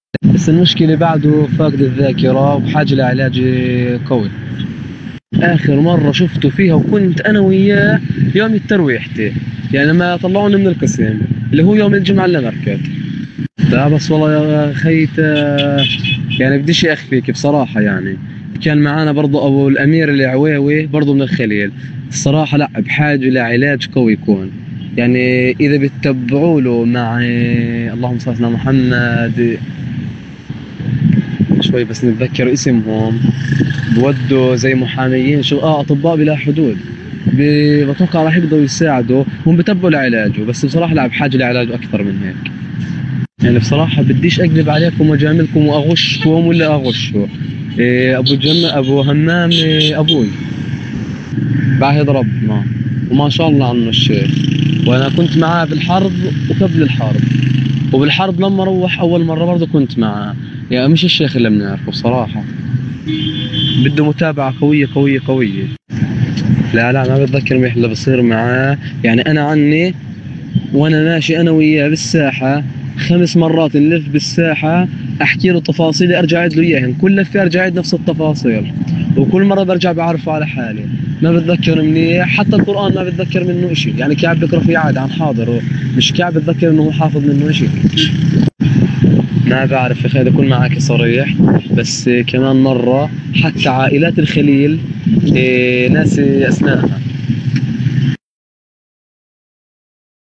شهادة احد الاسرى عن الحالة الصحية للاسير النتشة